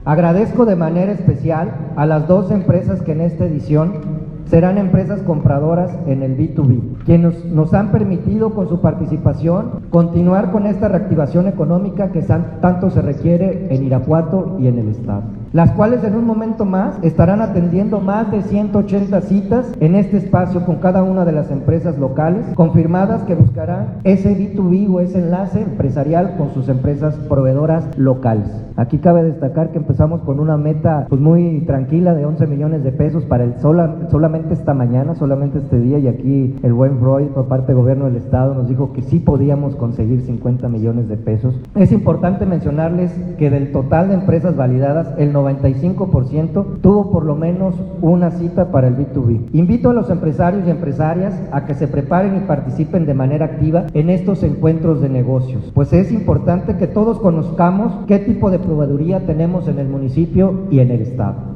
AudioBoletines
Lorena Alfaro García – Presidenta Municipal
Marco Antonio Rodríguez Vázquez, subsecretario de Trabajo y Previsión Social